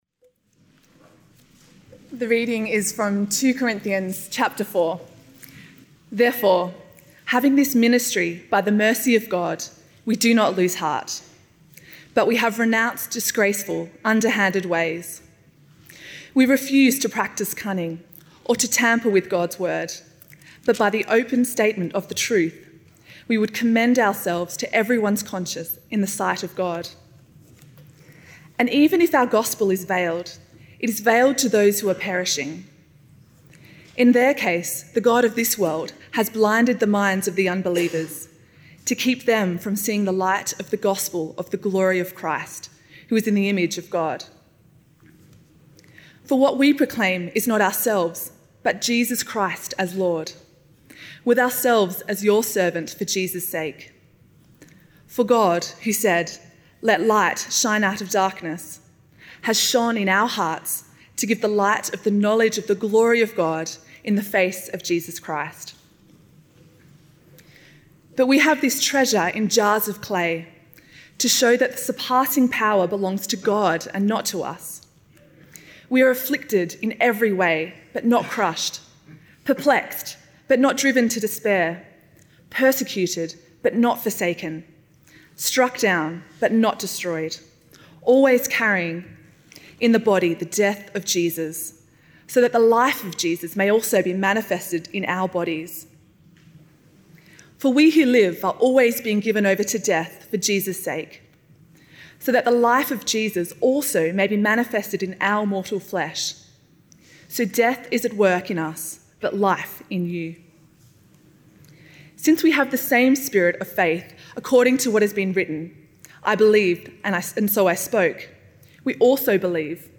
A talk preached at St Helens, London.